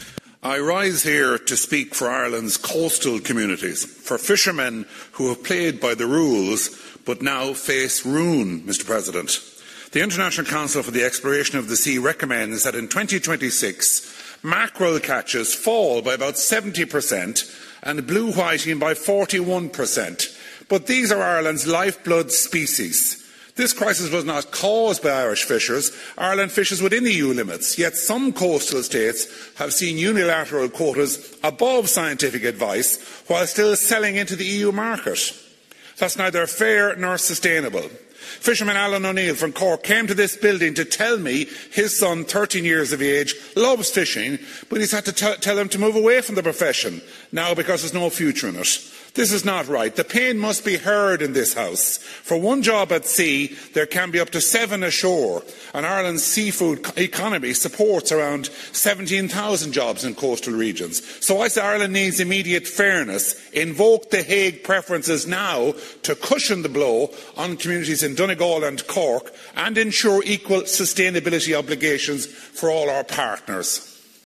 MEP Ciaran Mullooly says Donegal and Cork will suffer if nothing is done:
mep-mullooly-speaking-to-eu-parliament-on-the-crisis-facing-the-irish-fishing-industry-audio.mp3